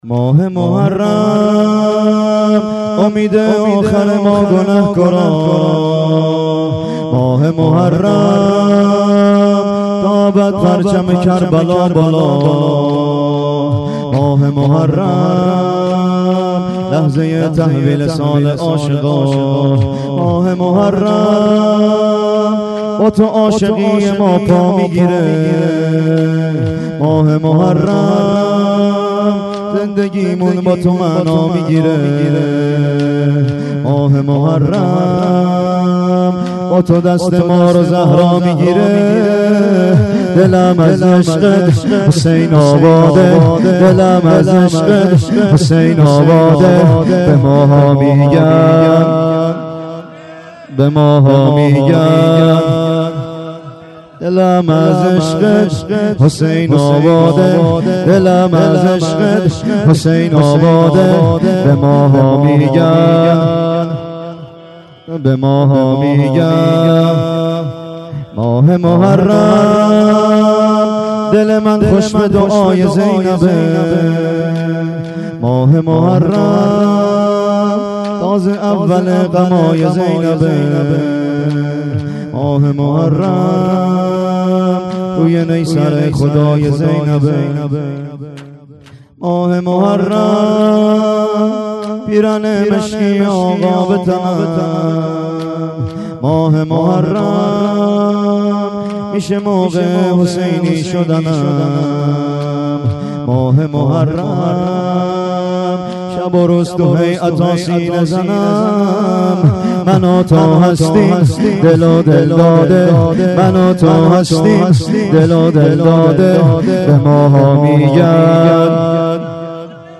گلچین شورهای محرم 93
شور شب دوم : ماه محرم امید آخر ما گنه کاراست